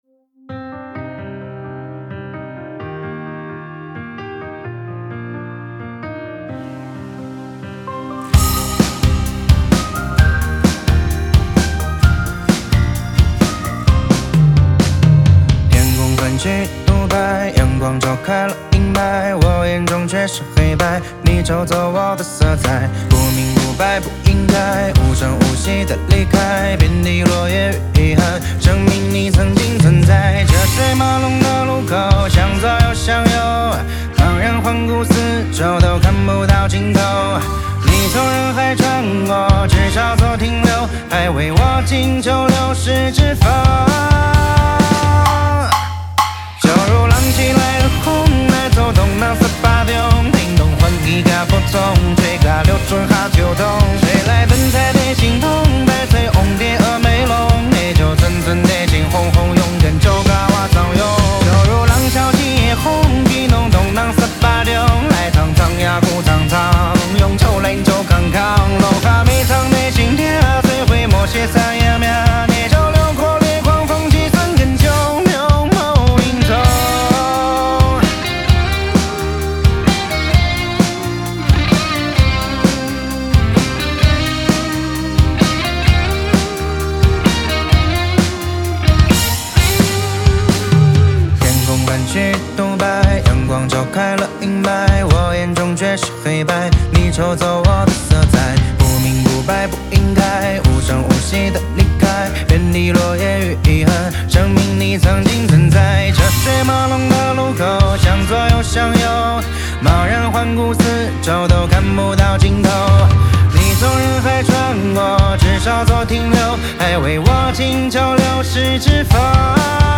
谱内音轨：架子鼓